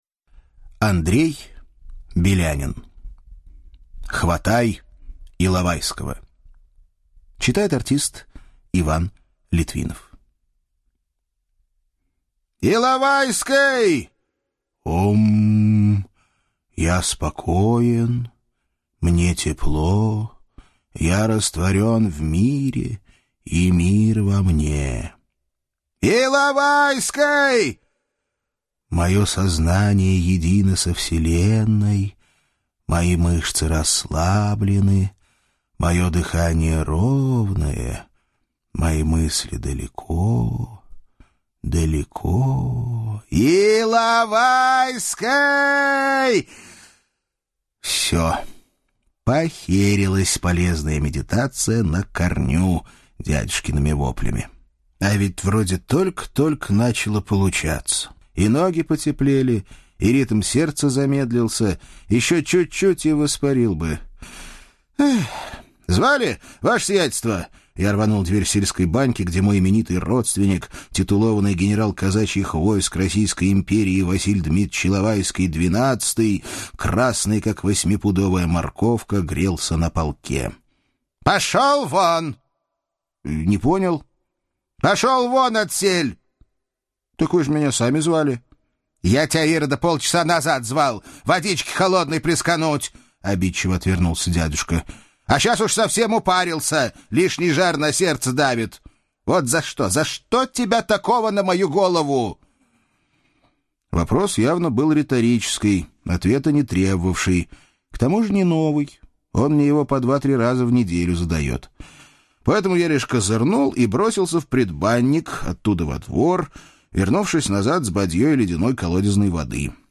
Аудиокнига Хватай Иловайского!